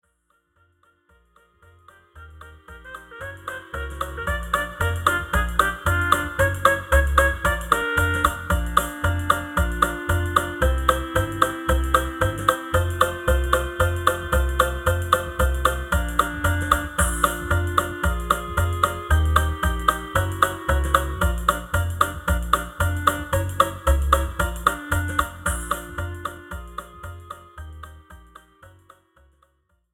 This is an instrumental backing track cover
• Key – B♭
• Without Backing Vocals
• No Fade